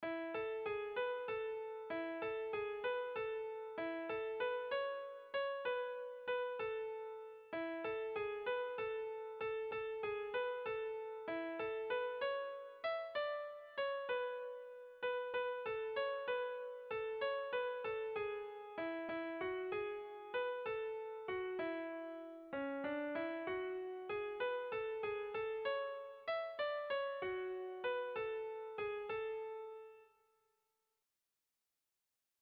Sentimenduzkoa
Zortziko handia (hg) / Lau puntuko handia (ip)
A1a2BD